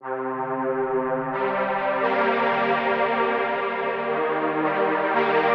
Orc Strings 03.wav